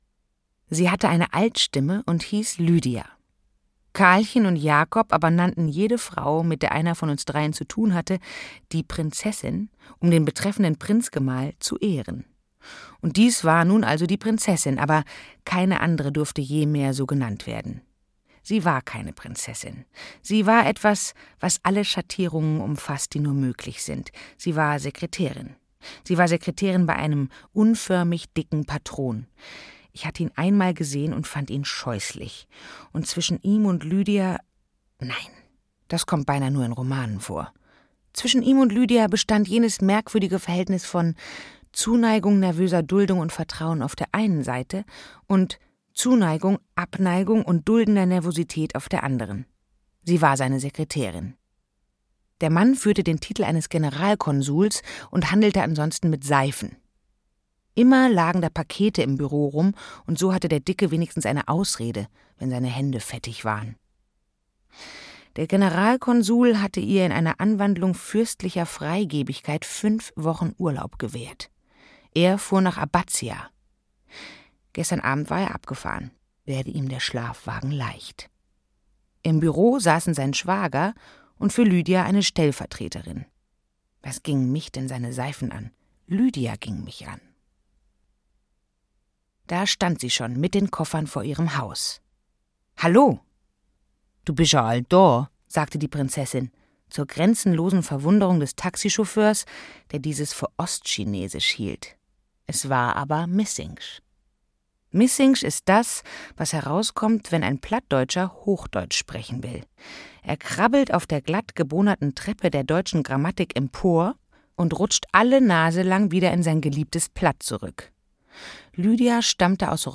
Schloß Gripsholm - Kurt Tucholsky - Hörbuch